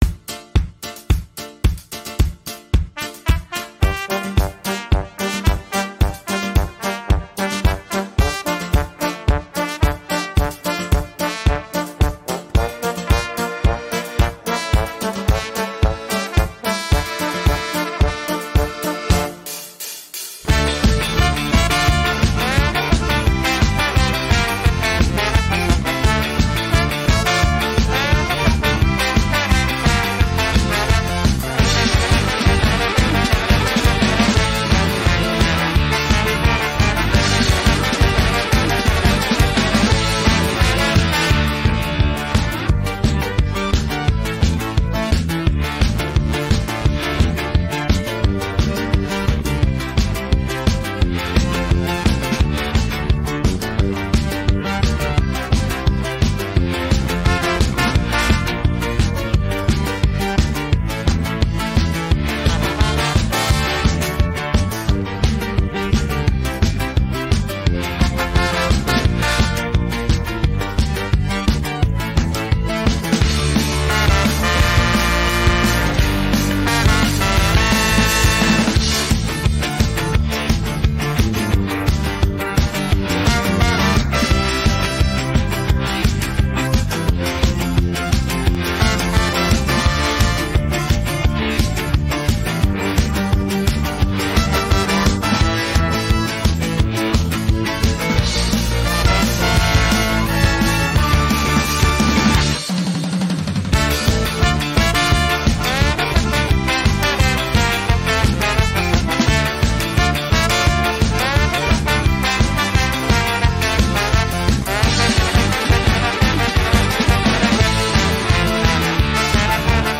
Безкоштовна мінусівка улюбленої пісні